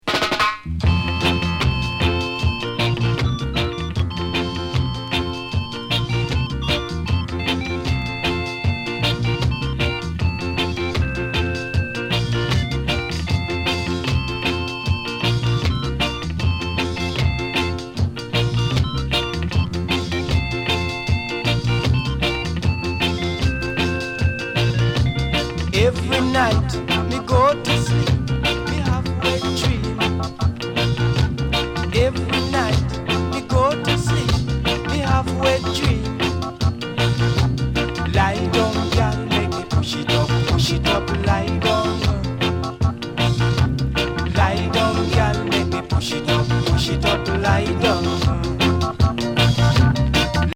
淫ドリーミーチューン